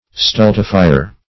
Stultifier \Stul"ti*fi`er\, n. One who stultifies.